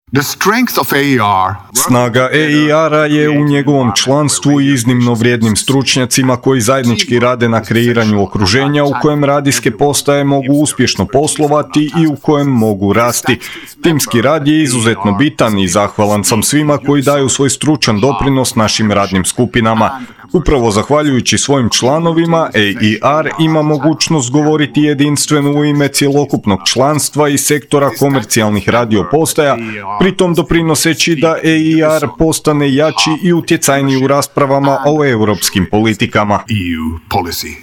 Tim povodom u petak je tradicionalno održan 5. Hrvatski radijski forum, na kojem su vodeći ljudi iz kulturne i kreativne industrije govorili upravo o Radiju i kreativnoj ekonomiji.